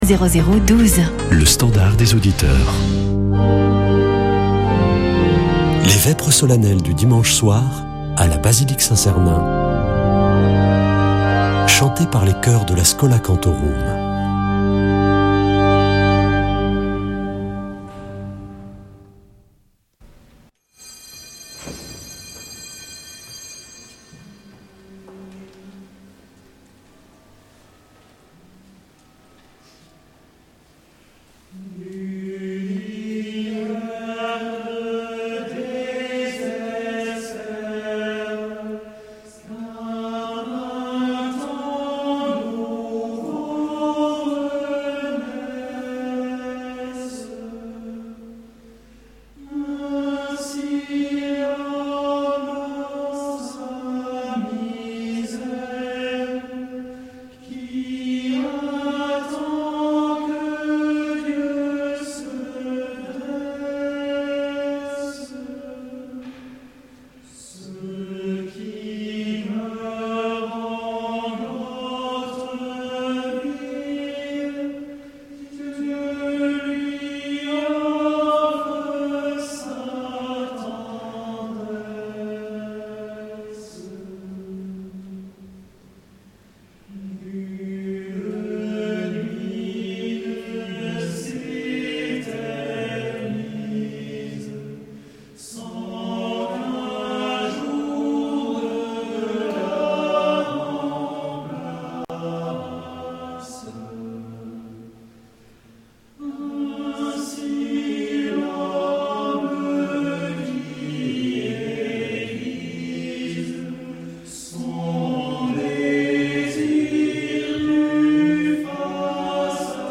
Vêpres de Saint Sernin du 23 mars
Une émission présentée par Schola Saint Sernin Chanteurs